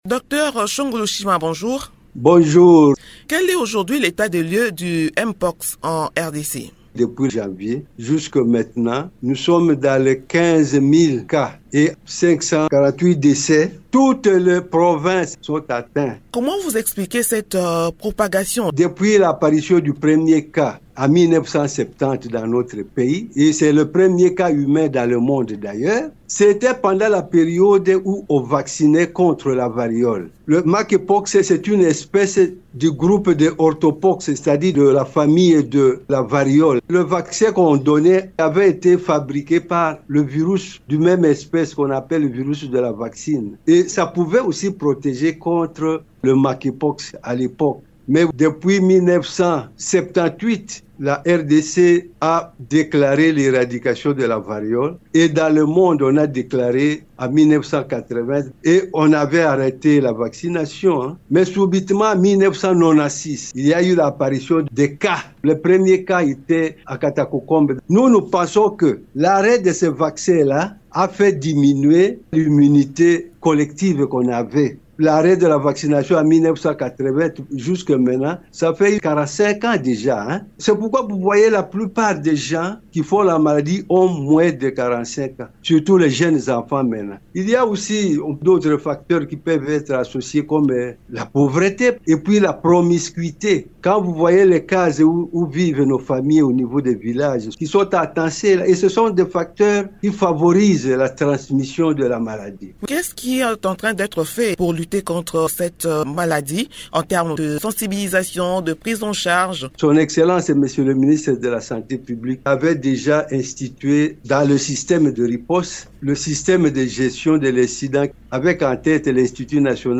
Invité de Radio Okapi, le Dr Shongo Lushima, directeur du Programme national de lutte contre le Monkeypox et les fièvres hémorragiques virales, assure que la riposte est en cours, malgré des défis logistiques importants.